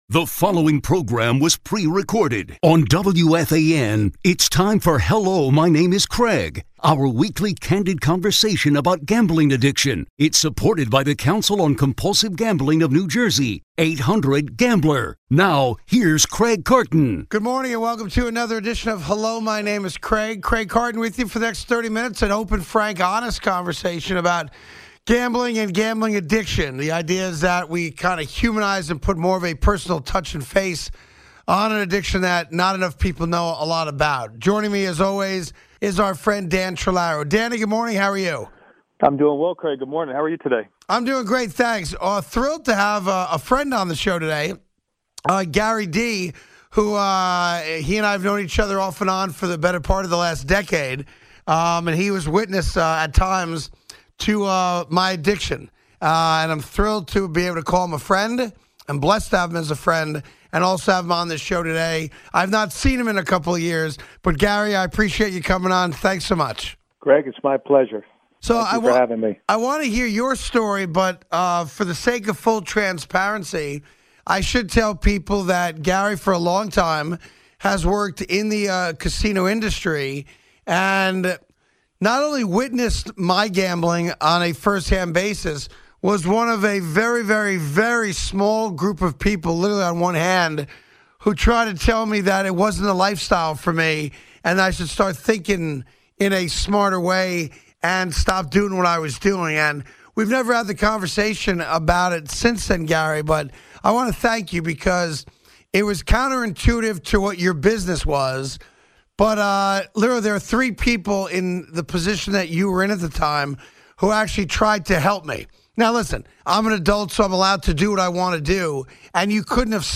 A WEEKLY CANDID CONVERSATION ON GAMBLING ADDICTION